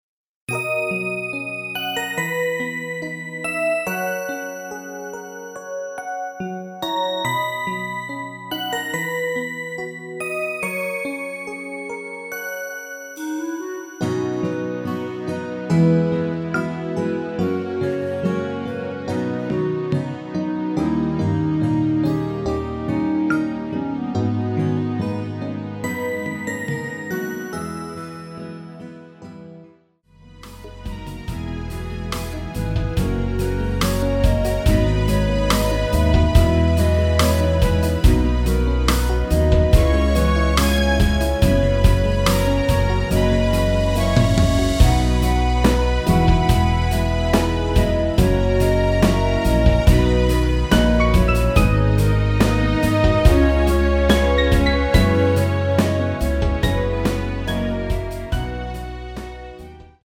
<간주 없이 아래 가사로 바로 진행 됩니다.>
멜로디 MR이라고 합니다.
앞부분30초, 뒷부분30초씩 편집해서 올려 드리고 있습니다.
중간에 음이 끈어지고 다시 나오는 이유는
위처럼 미리듣기를 만들어서 그렇습니다.